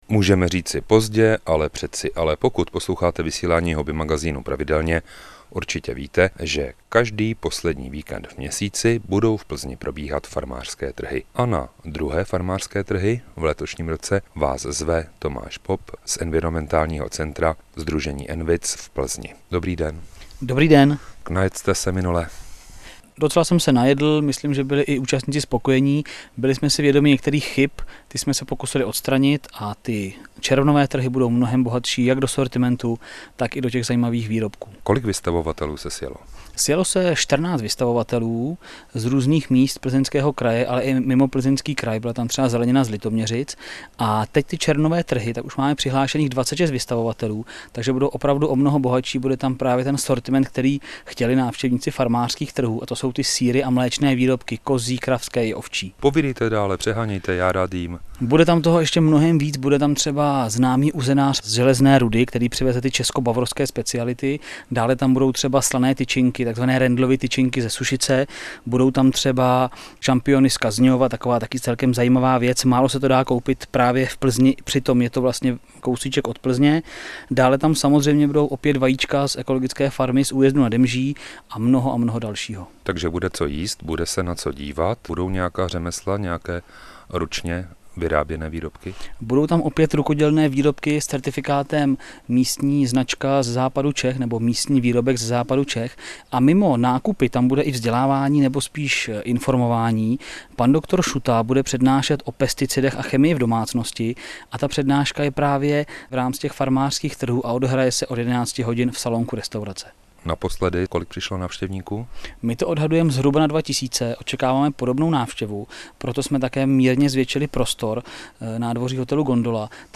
Přikládáme i záznam z vysílání ČRO Plzeň.